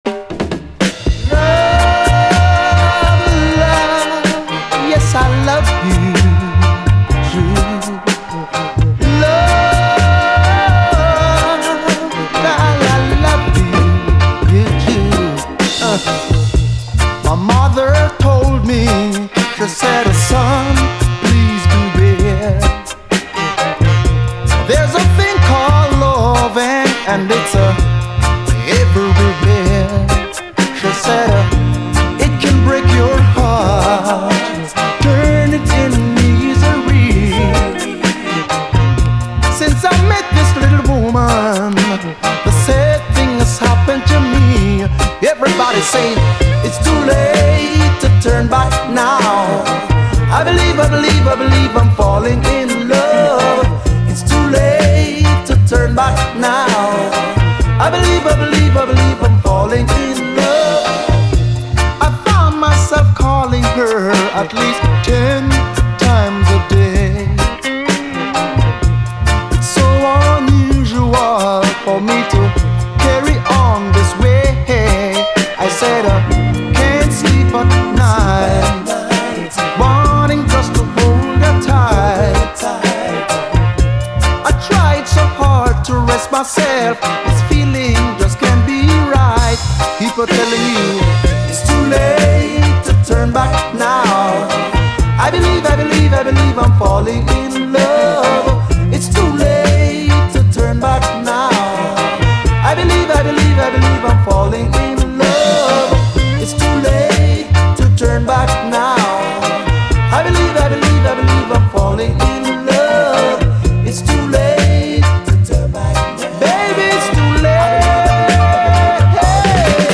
REGGAE
太いブラスなどソウルフルでコク深い上質な演奏による最高ジャマイカン・ソウルです！